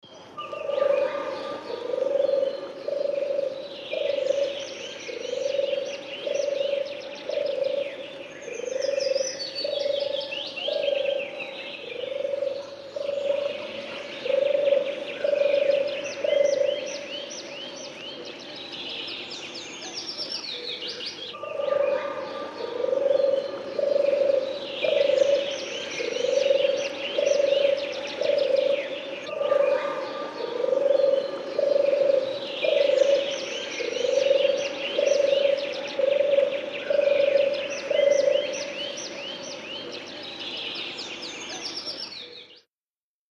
Turtle doves. ( California )